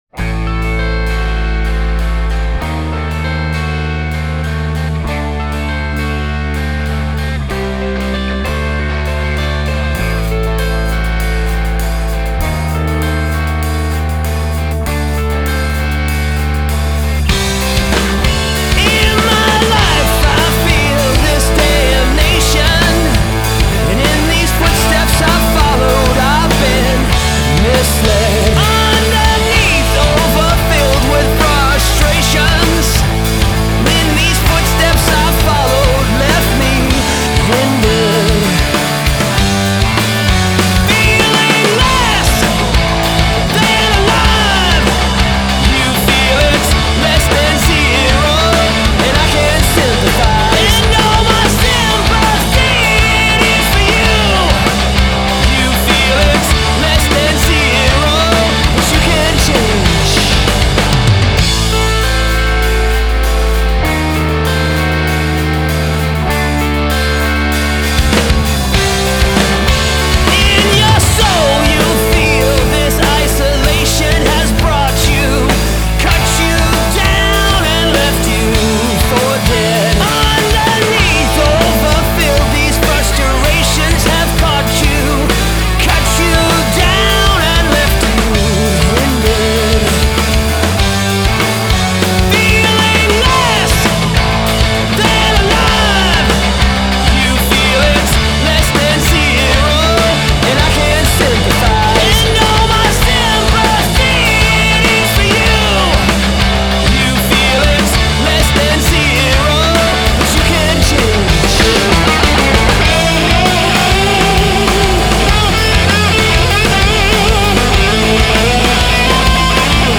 Alternative Rock (Full Band)